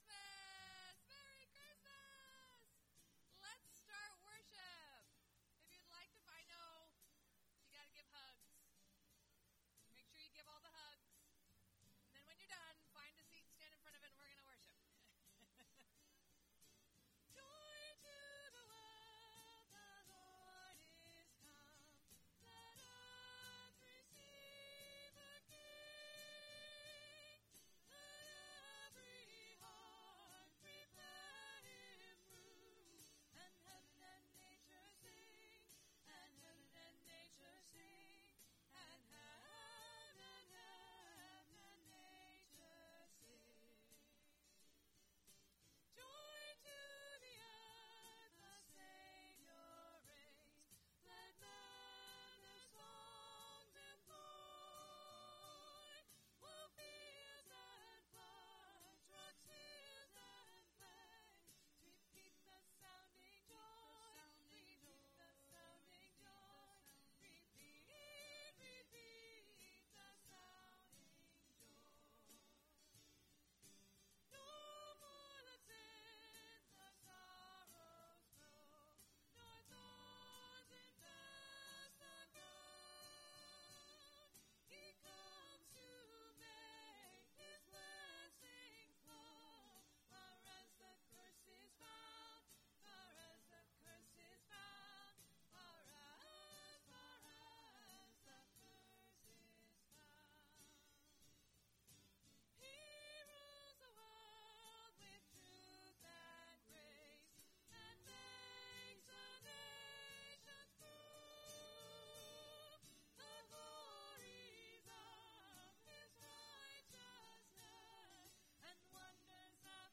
Christmas Eve Service 2025.mp3